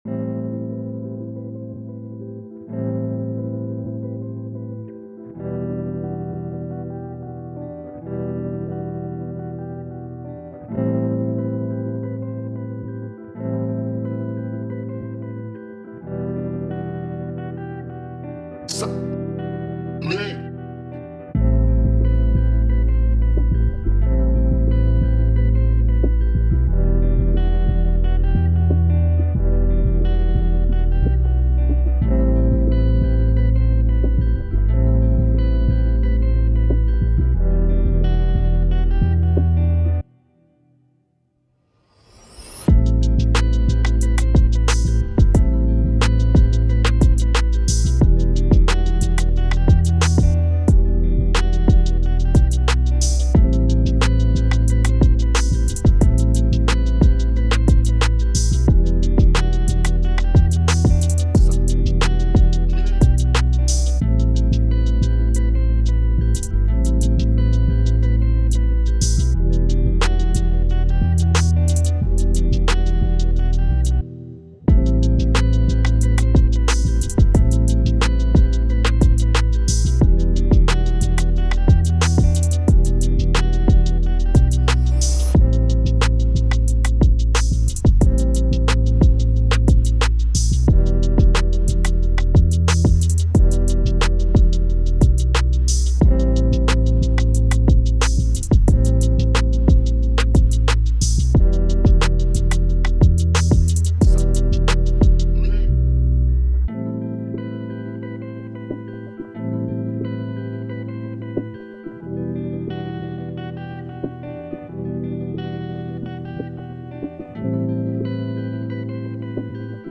Positive, Calm, Chill, Vibe
Eletric Guitar, Drum, Piano